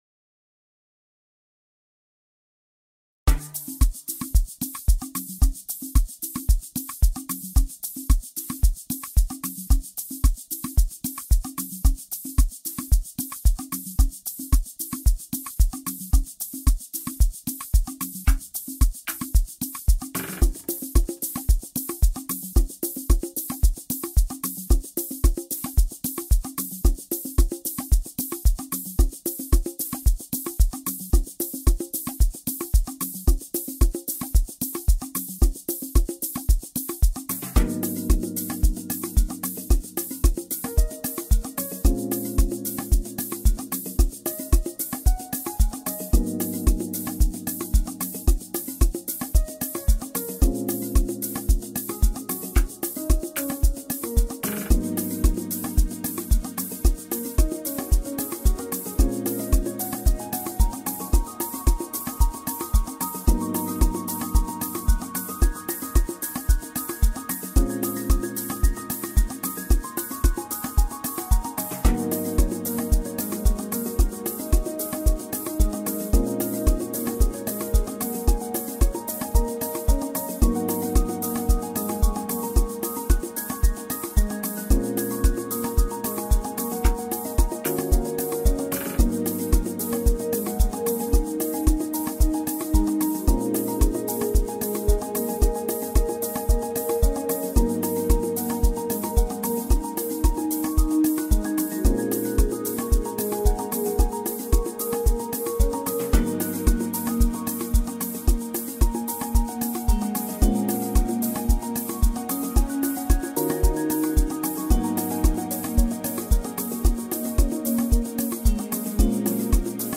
soulful and Private School Amapiano